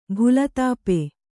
♪ bhulatāpe